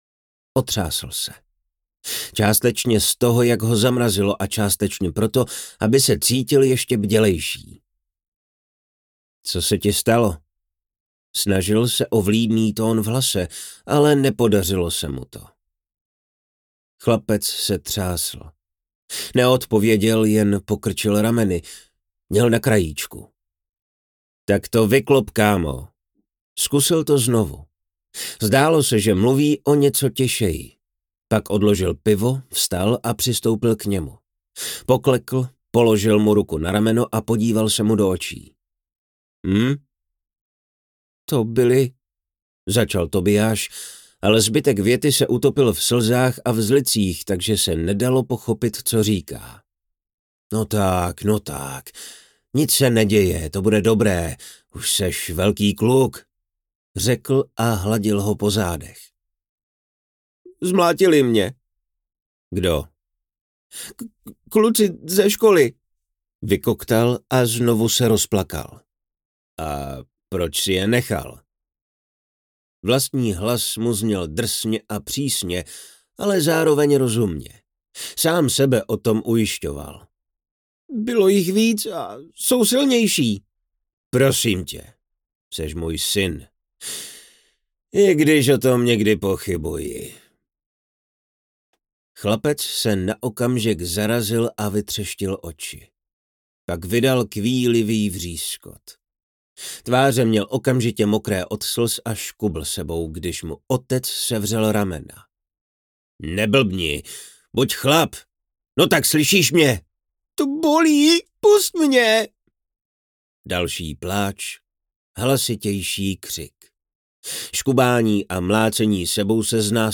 Kronika smrti audiokniha
Ukázka z knihy